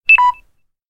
جلوه های صوتی
دانلود صدای ضعیف یا کم شدن باتری گوشی از ساعد نیوز با لینک مستقیم و کیفیت بالا